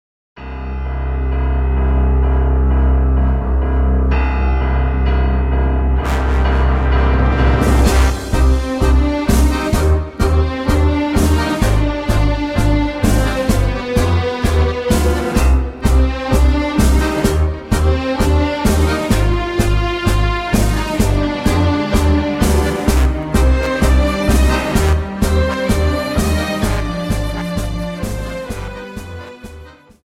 Dance: Tango 32